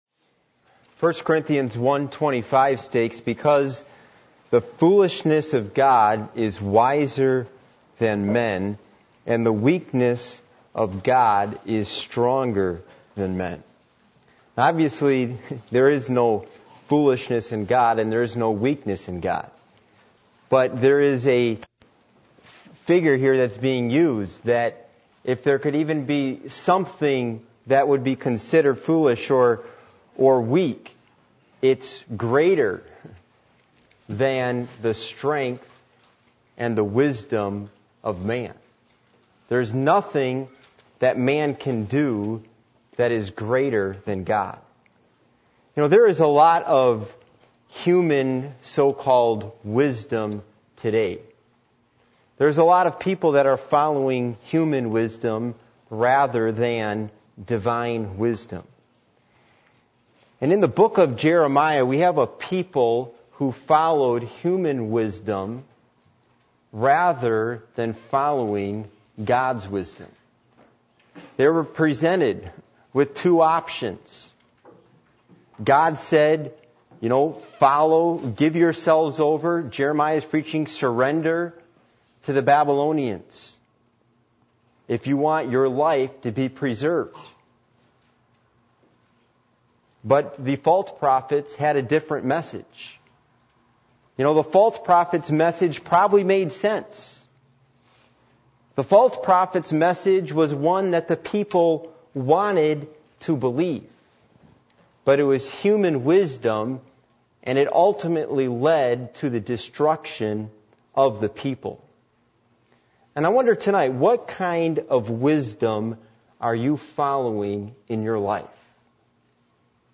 Passage: 1 Corinthians 1:25 Service Type: Midweek Meeting %todo_render% « Do We Thirst For God?